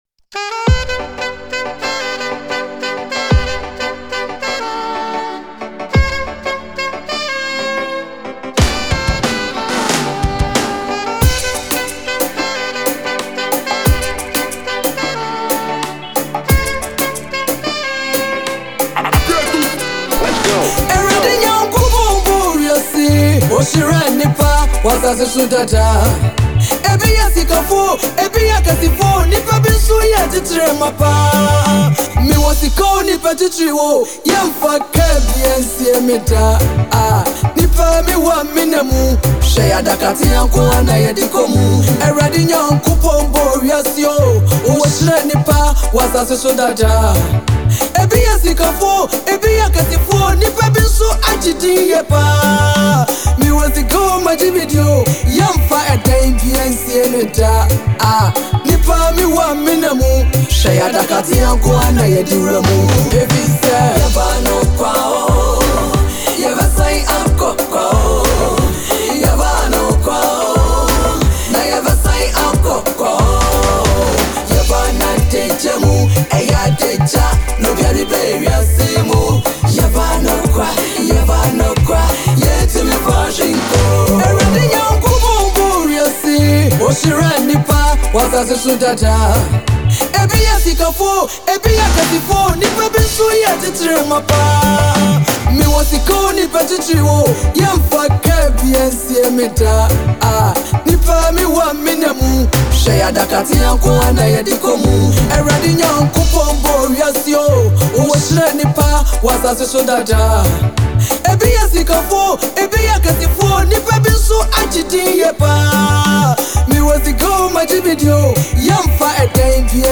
Gospel Music
an uplifting and spiritually rich gospel song
• Genre: Gospel